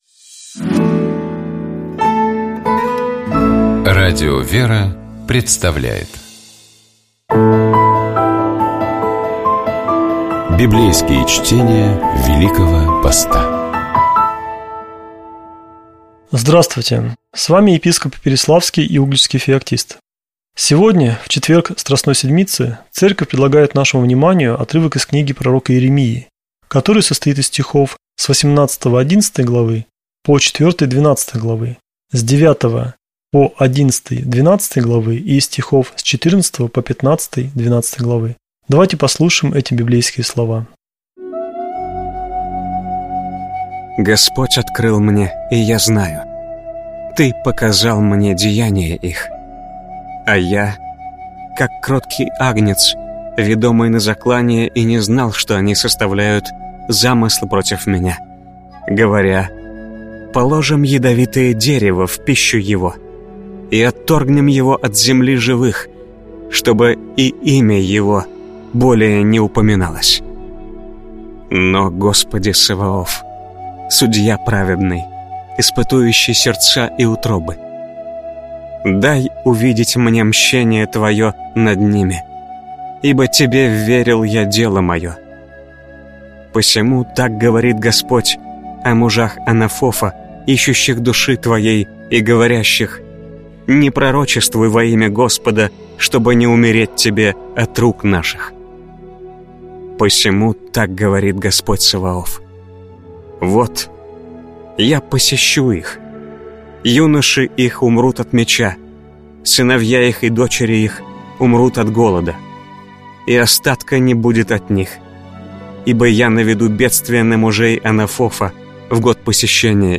Библейские чтения
Читает и комментирует